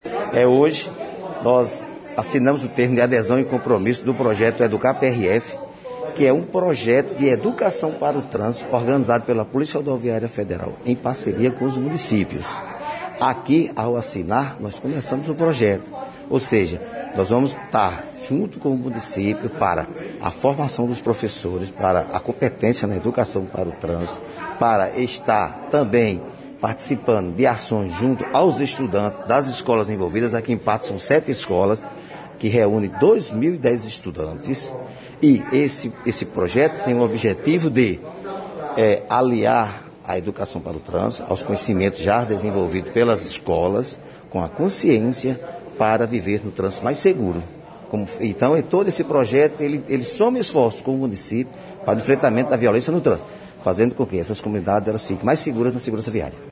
Aconteceu na manhã desta sexta-feira (26) na sede da Delegacia da PRF Patos a assinatura de um Termo de Adesão e Compromisso entre a Prefeitura de Patos, Polícia Rodoviária Federal e Secretaria Municipal de Educação, ao Projeto EDUCAR PRF.